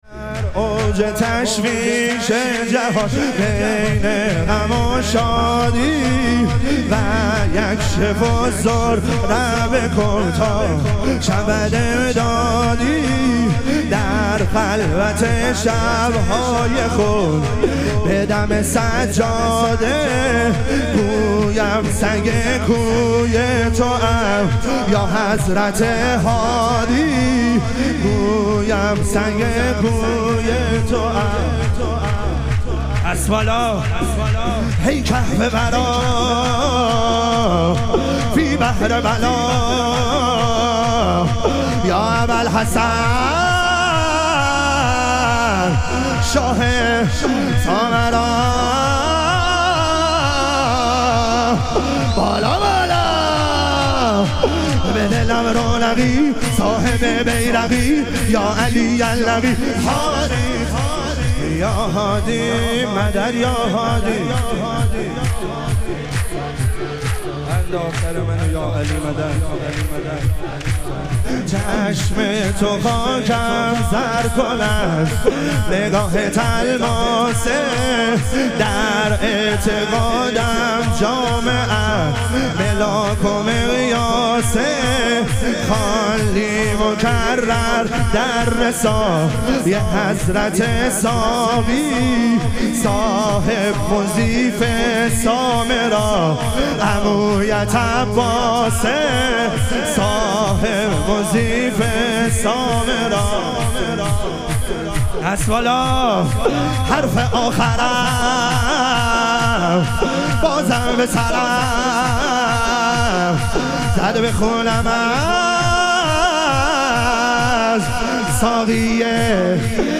ظهوروجود مقدس امام هادی علیه السلام - شور